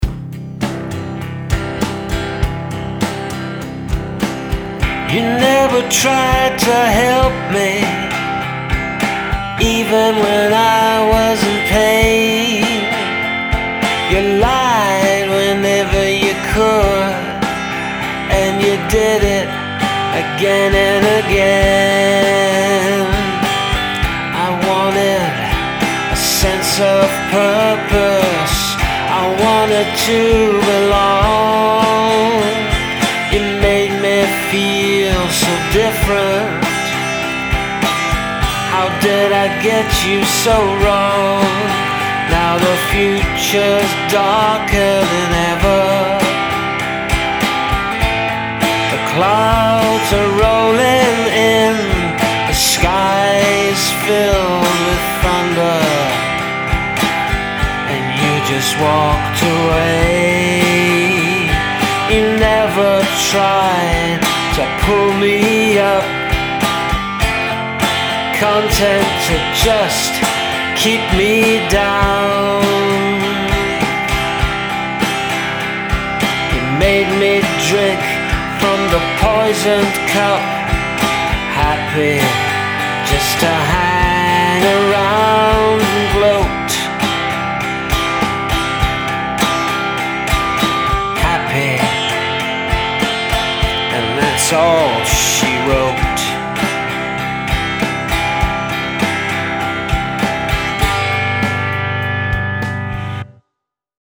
Really dig the lo-fi vibe of this demo and how you paint a vivid picture of malice and regret in just a few short lines -- really great take on the prompt.